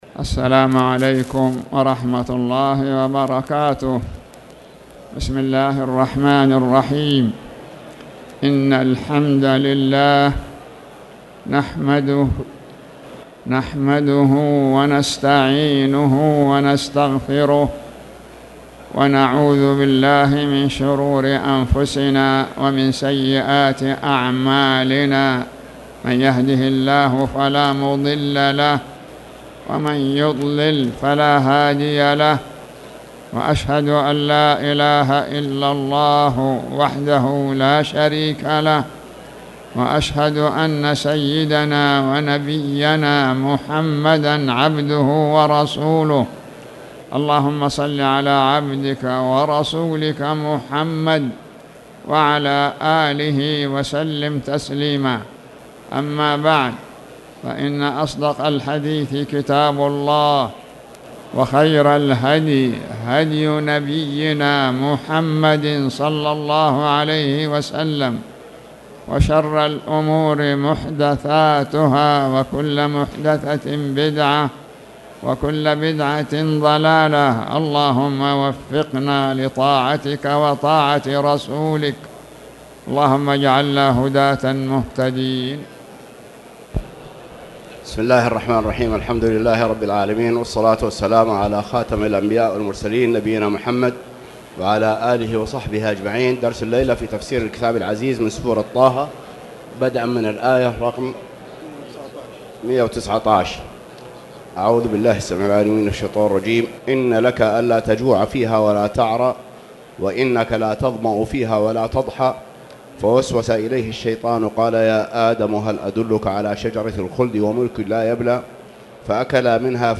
تاريخ النشر ١٩ شعبان ١٤٣٨ هـ المكان: المسجد الحرام الشيخ